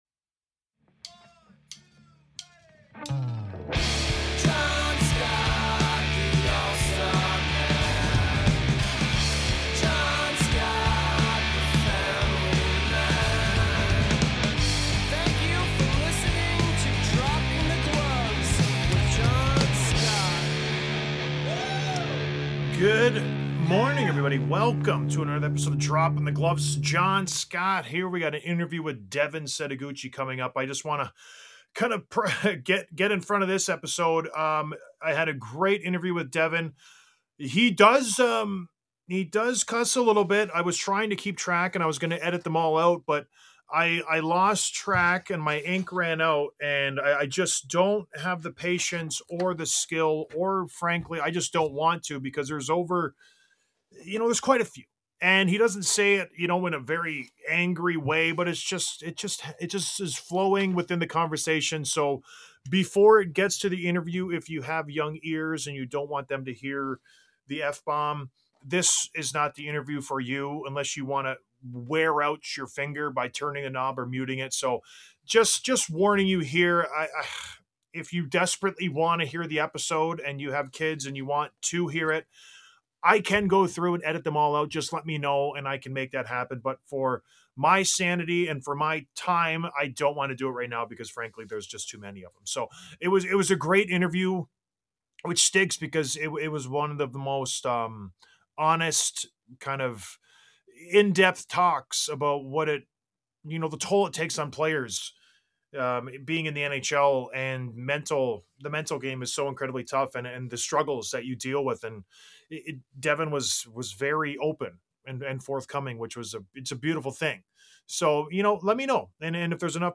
Interview w/ Devin Setoguchi